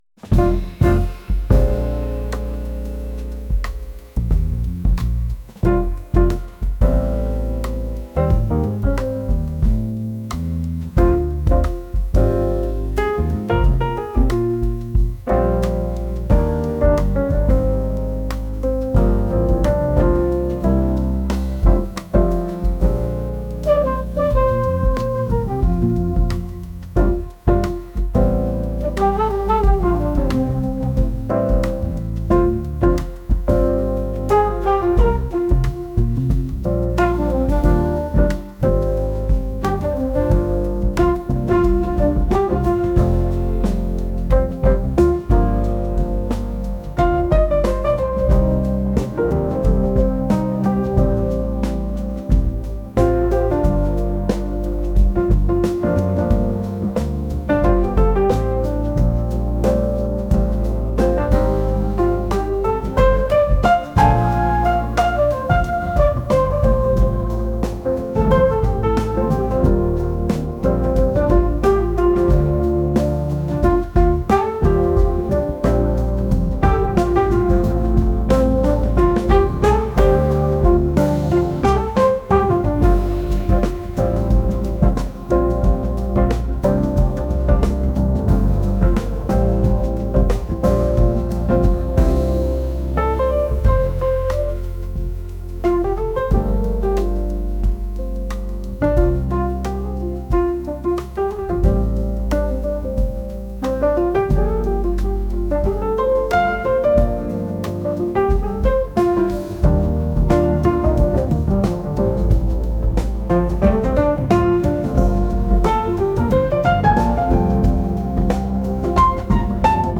jazz | soulful | lounge